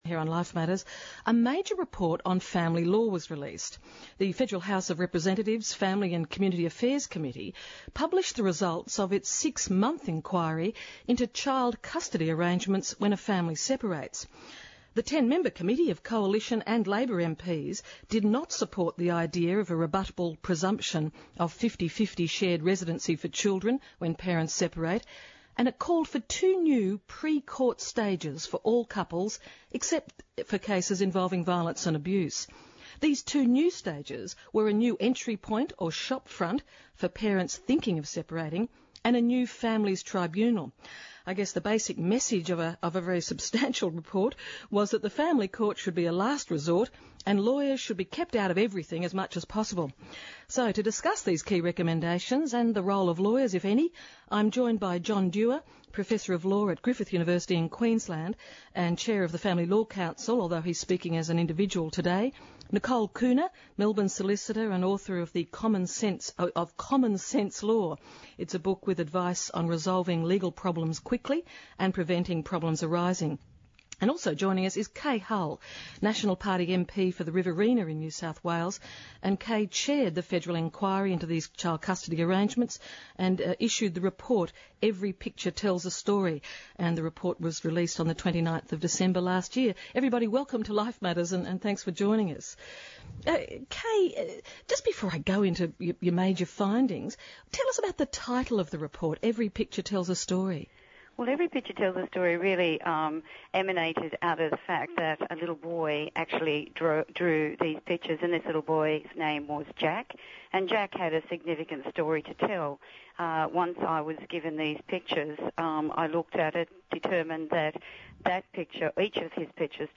F4J News - Custody Inquiry-Interview
Custody Inquiry-Interview 27/01/2004 Make a Comment Contributed by: admin ( 100 articles in 2004 ) Listen to an interview from the program "Life Matters" on the recent inquiry into Family Law, child custody and child support.